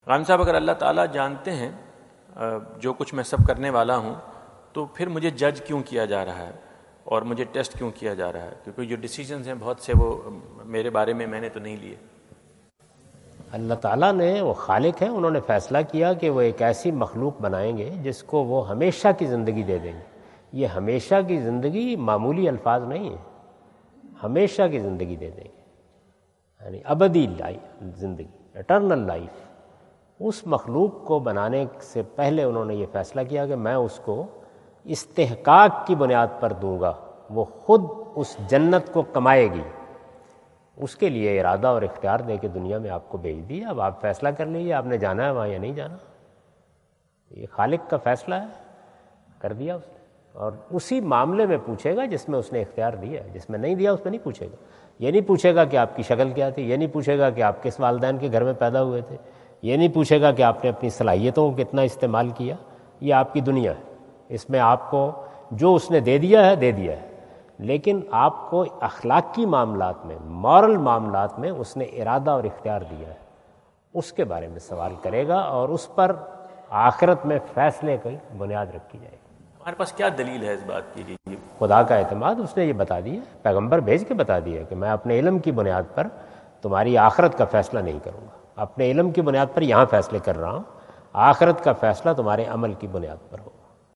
Javed Ahmad Ghamidi answer the question about "When everything is predestined, why day of punishment?" During his US visit at Wentz Concert Hall, Chicago on September 23,2017.